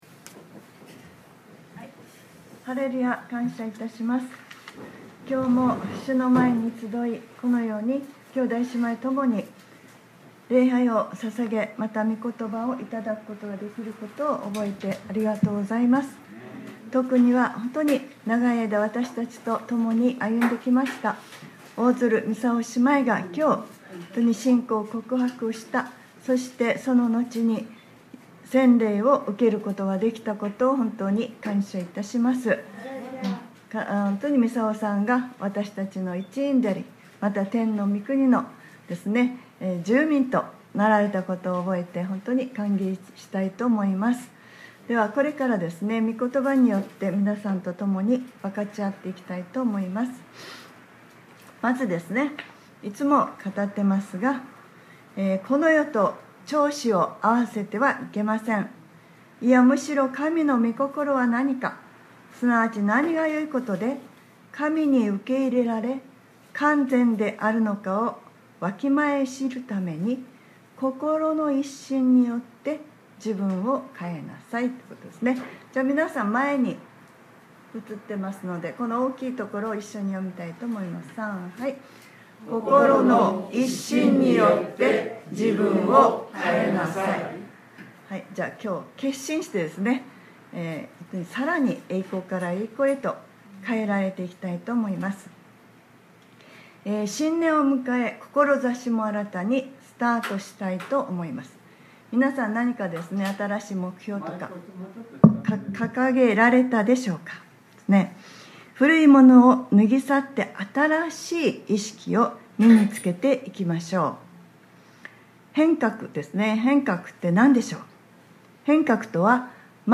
2022年01月16日（日）礼拝説教『 万人祭司：愛すること 』 | クライストチャーチ久留米教会